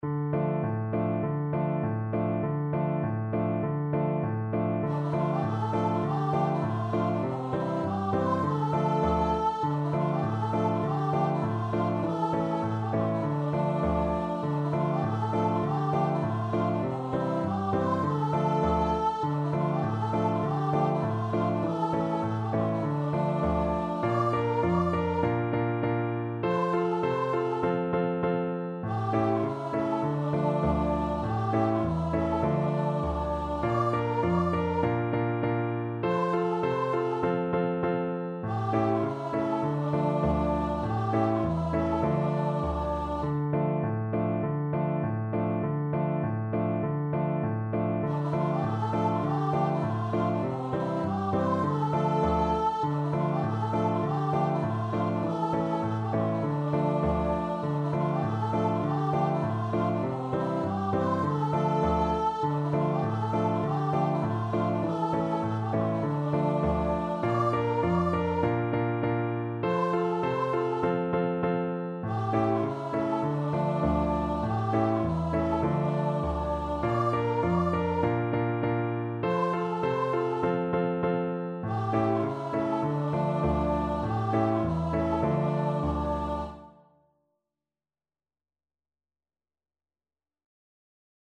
4/4 (View more 4/4 Music)
Traditional (View more Traditional Voice Music)
Bolivian